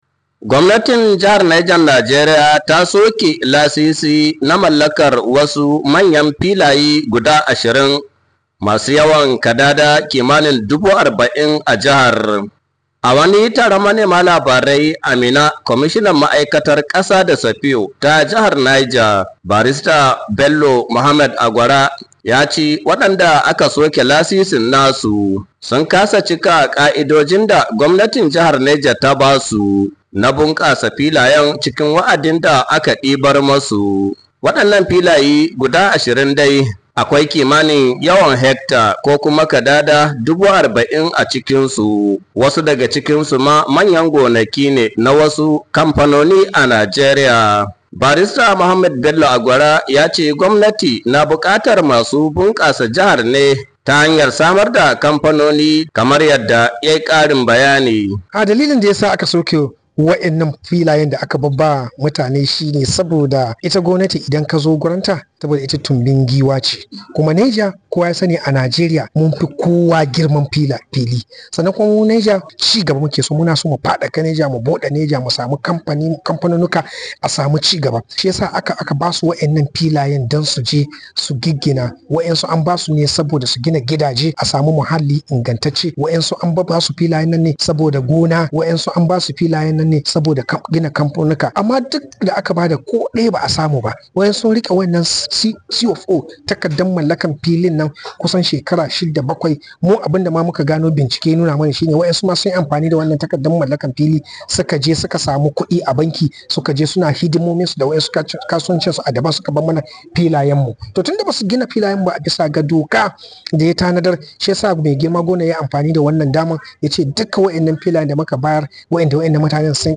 Awani taron manema labarai a Minna Kwamishinan Ma’aikatar Kasa da safiyo na Jihar Barista Bello Muhammad Agwara yace wadanda aka soke lasisin nasu sun kasa cika ka’idojin da Gwamnatin Jihar ta basu na bunkasa filayen cikan wa,adin da aka dibar masu,